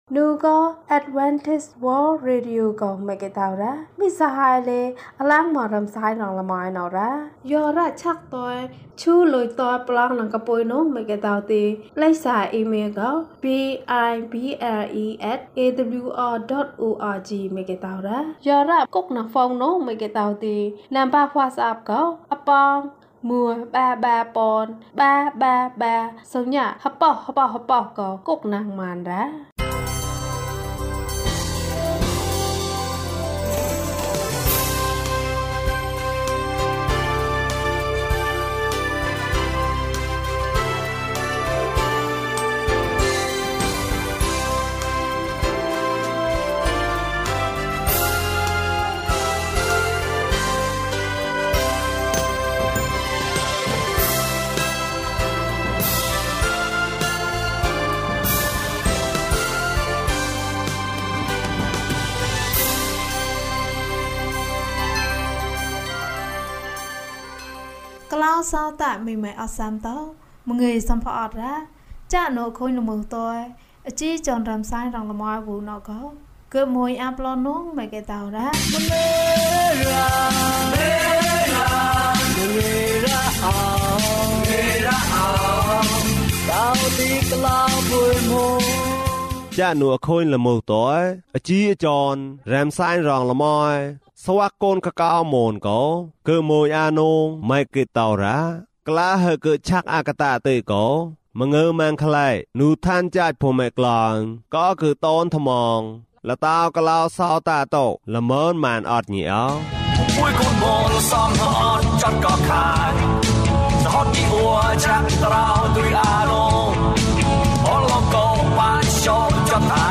ခရစ်တော်ထံသို့ ခြေလှမ်း ၁၈။ ကျန်းမာခြင်းအကြောင်းအရာ။ ဓမ္မသီချင်း။ တရားဒေသနာ။